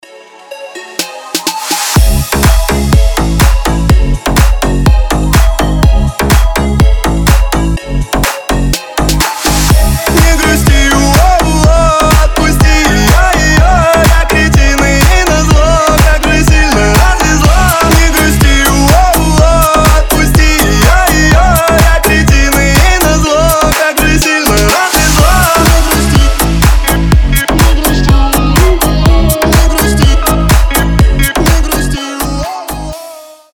• Качество: 320, Stereo
мужской голос
громкие
Club House
ремиксы